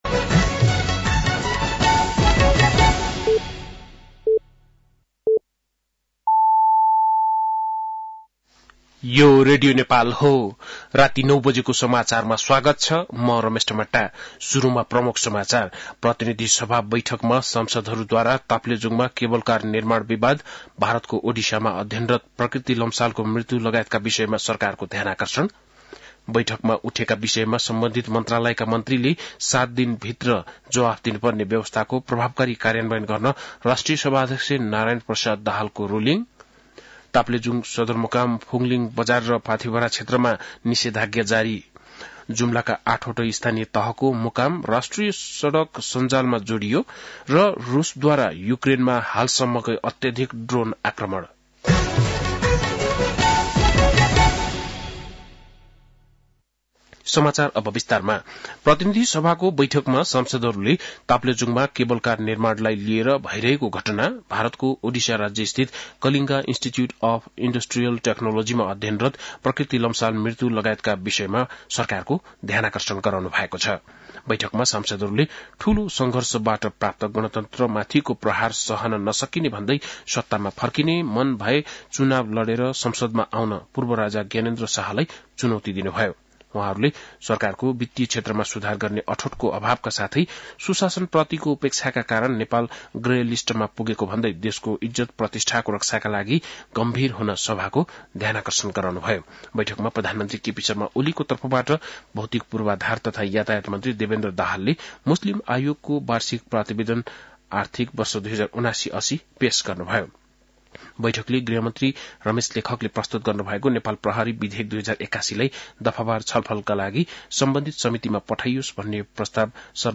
बेलुकी ९ बजेको नेपाली समाचार : १२ फागुन , २०८१
9-pm-nepali-news-11-11.mp3